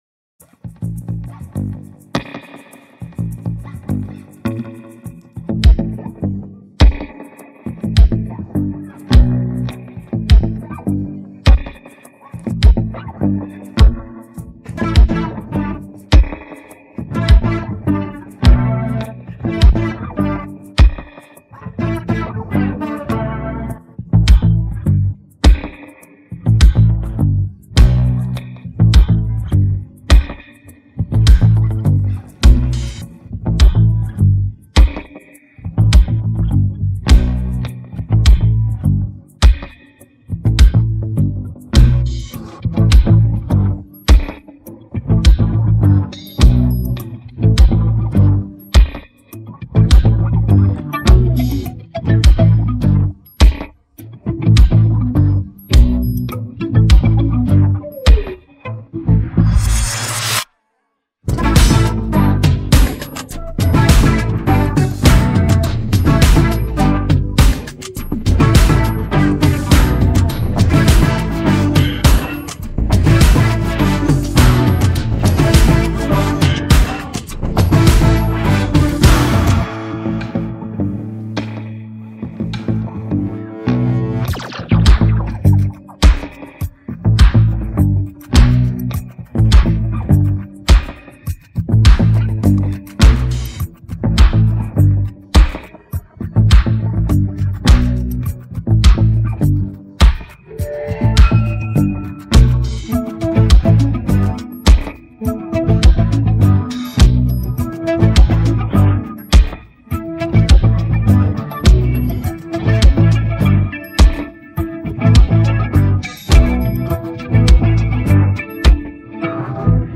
Pop music